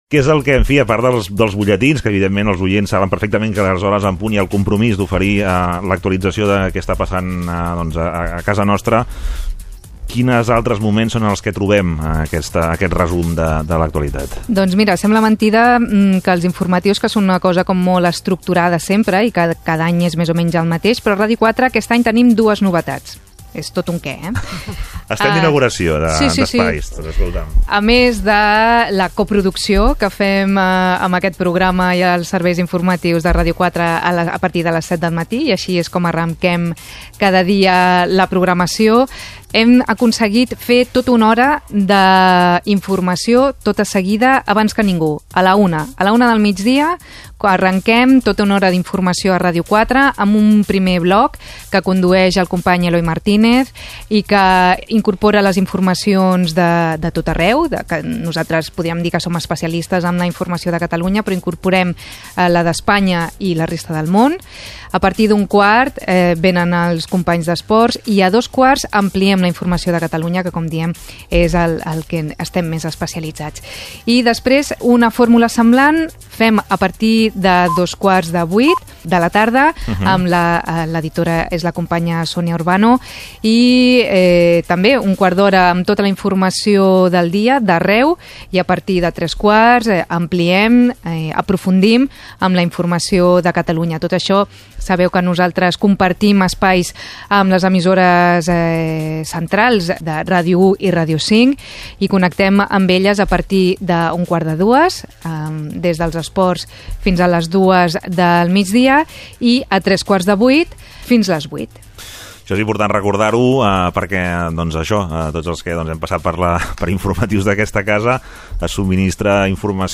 El matí de Ràdio 4: els serveis informatius - Ràdio 4, 2018